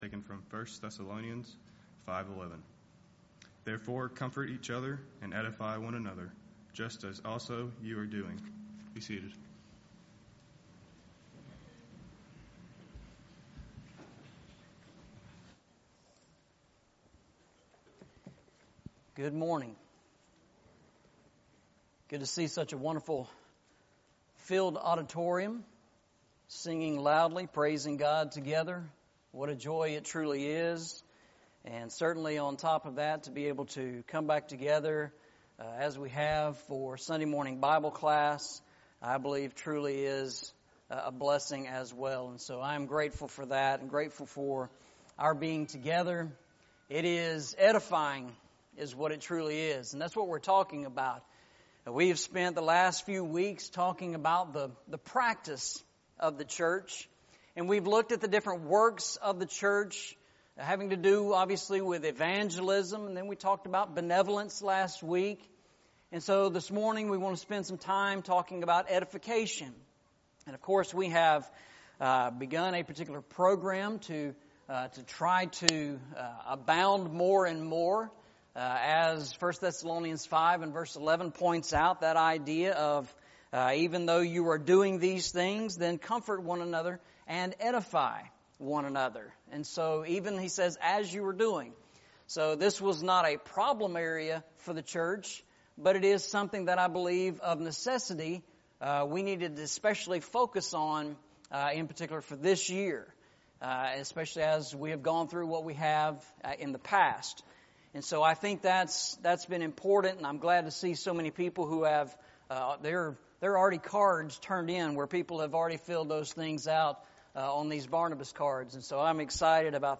Eastside Sermons
1 Thessalonians 5:11 Service Type: Sunday Morning « The Pattern of the Church